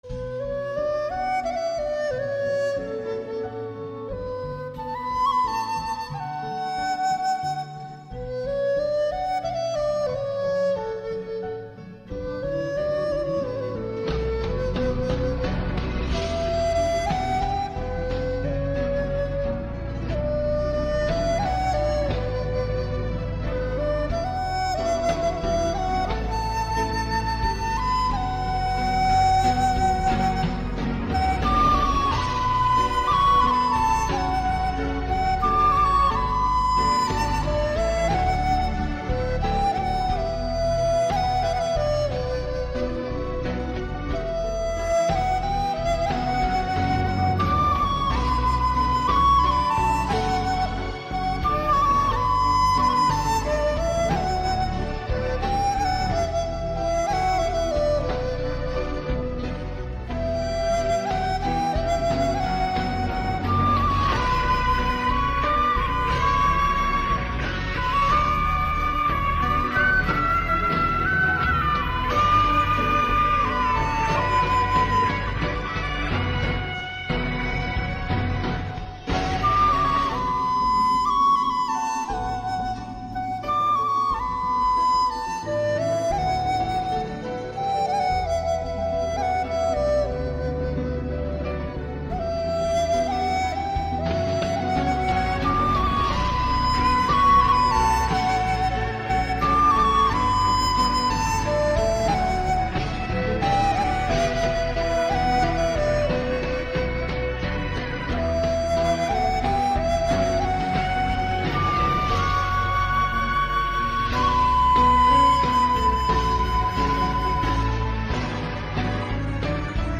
giai điệu cổ phong sâu lắng.